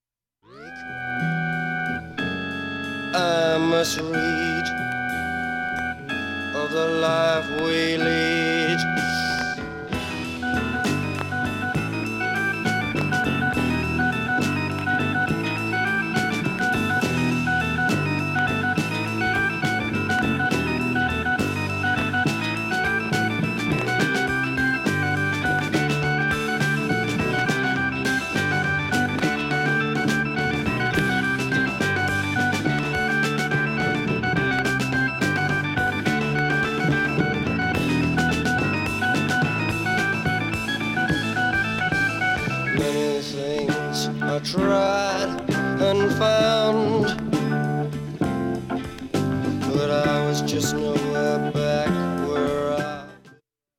クリアないい音質良好全曲試聴済み。
５０秒の間に周回プツ出ますがかすかです。